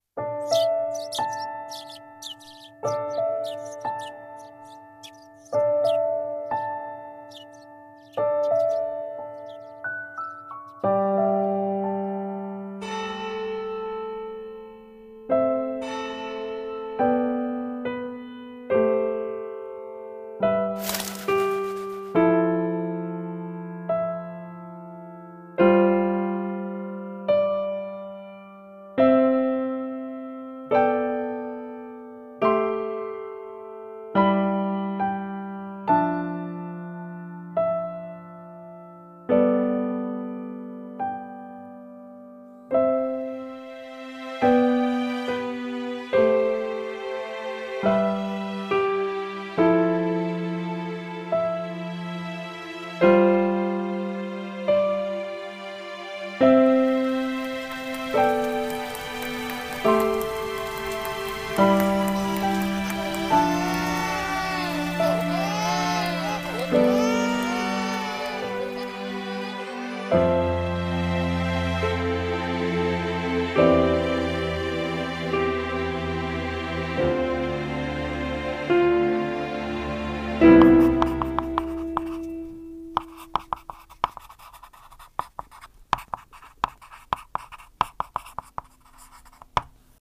CM風声劇「.letter